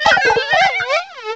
-Replaced the Gen. 1 to 3 cries with BW2 rips.